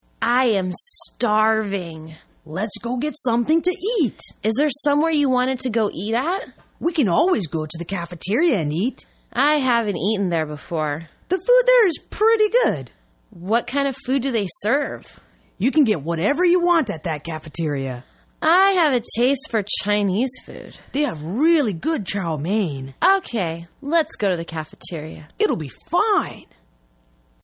英语情景对话：Foods Available at the Cafeteria(2) 听力文件下载—在线英语听力室